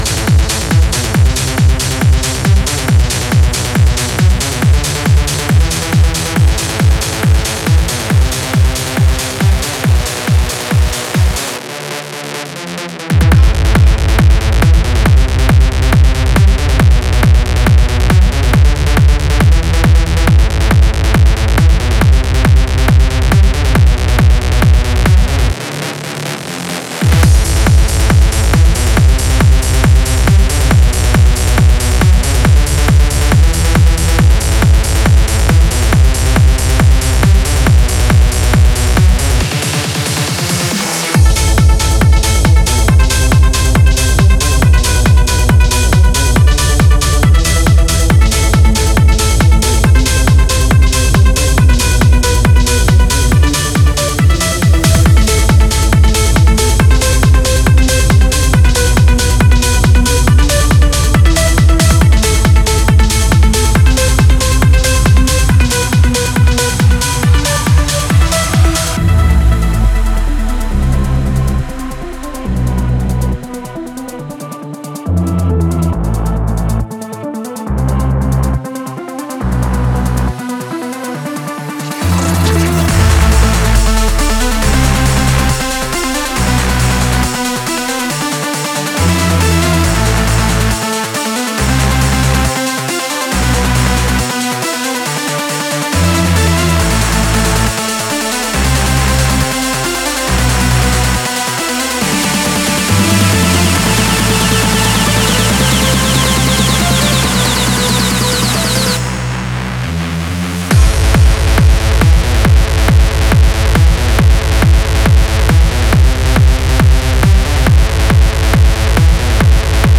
Styl: Techno, Trance